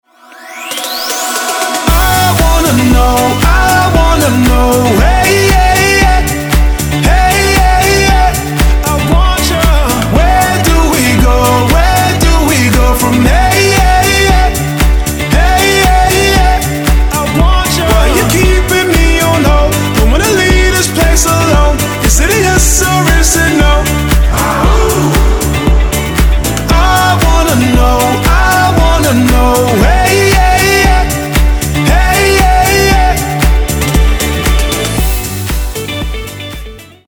• Качество: 192, Stereo
мужской вокал
dance